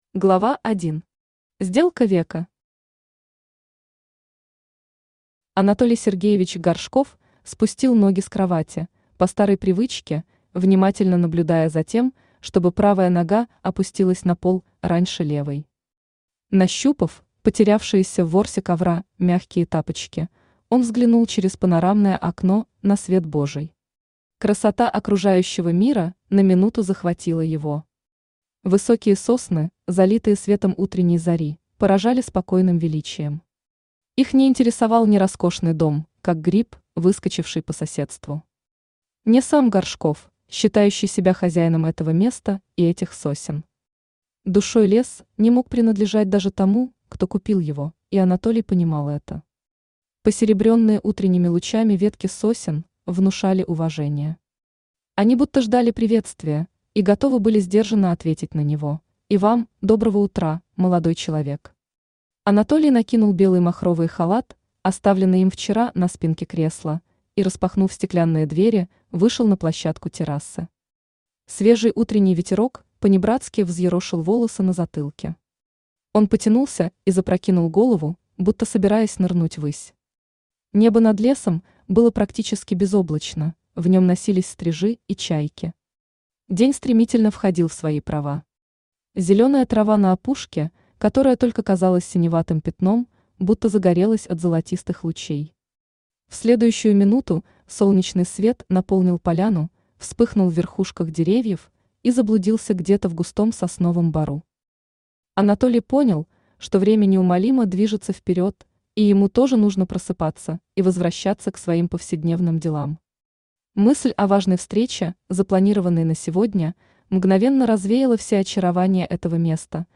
Aудиокнига Один из рода Автор Юлия Валерьевна Шаманская Читает аудиокнигу Авточтец ЛитРес.